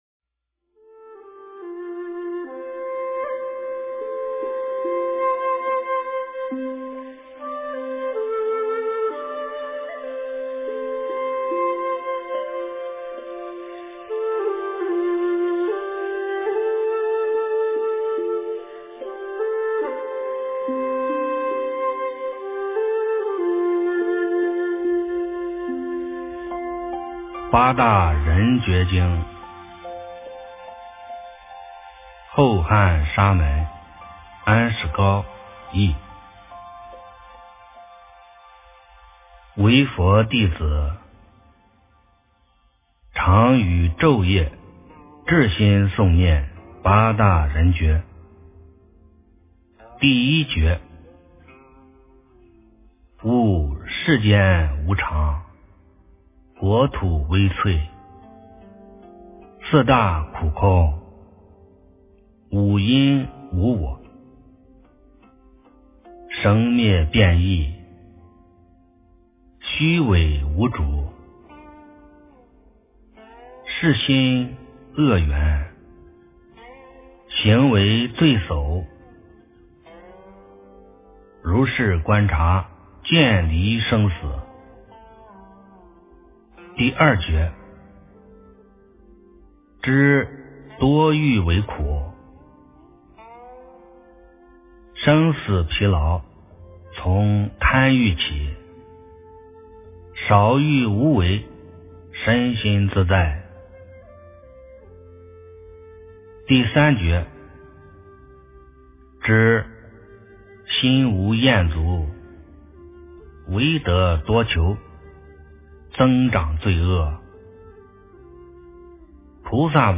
佛说八大人觉经 - 诵经 - 云佛论坛
佛音 诵经 佛教音乐 返回列表 上一篇： 金刚经 下一篇： 《华严经》26卷 相关文章 大悲十小咒--佛光山梵呗团 大悲十小咒--佛光山梵呗团...